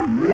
AFX_DROIDTALKSHORT_2_DFMG.WAV
Droid Talk Short 2